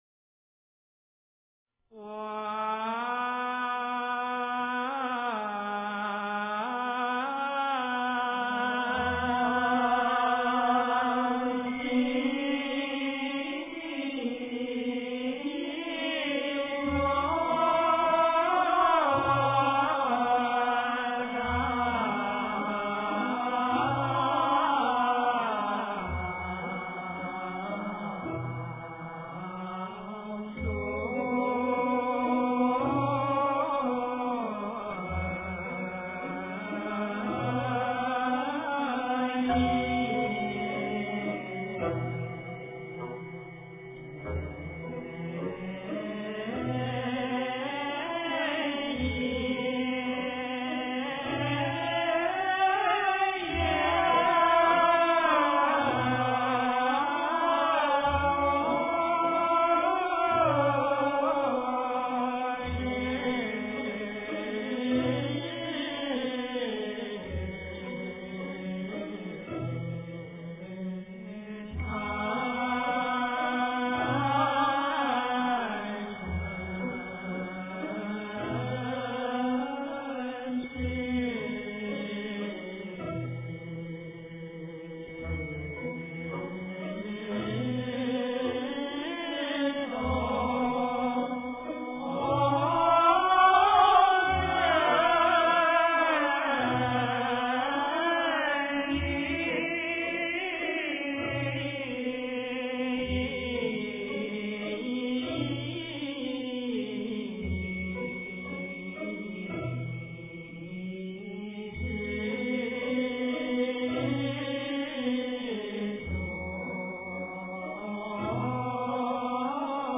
经忏
佛教音乐